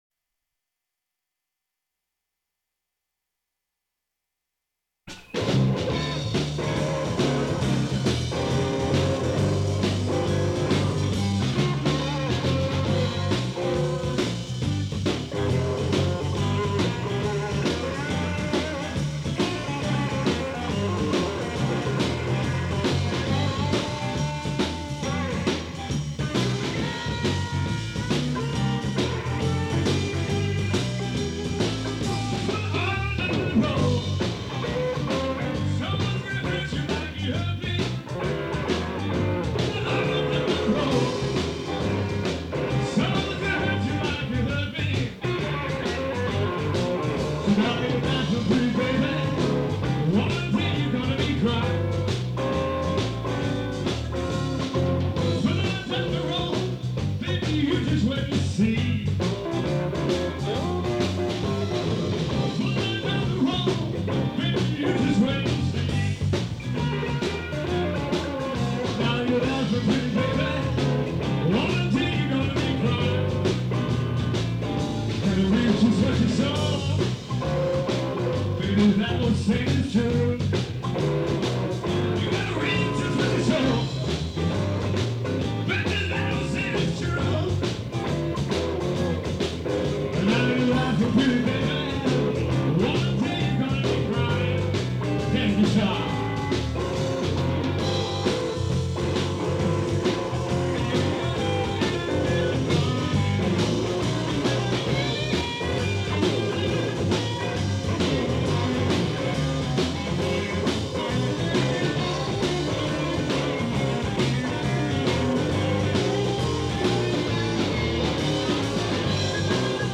Live at Rebo’s in Redondo Beach